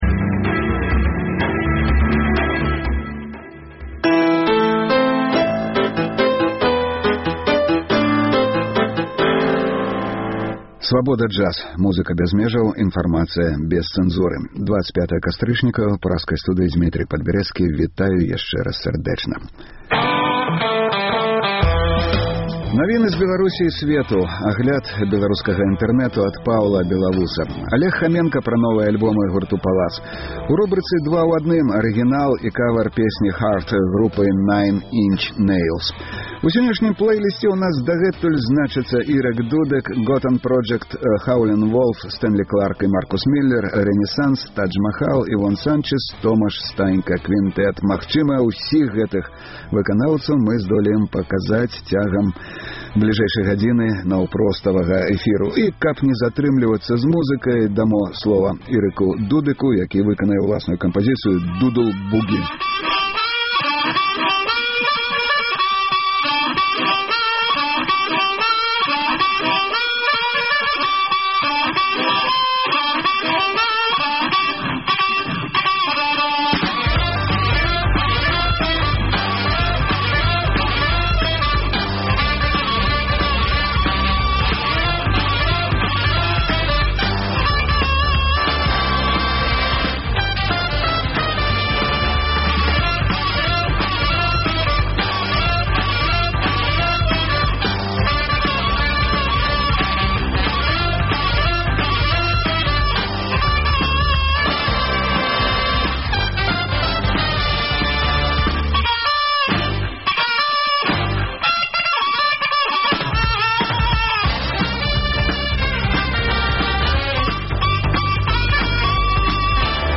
Інтэрнэт-радыё Svaboda Jazz. Слухайце ад 12:00 да 14:00 жывы эфір Свабоды!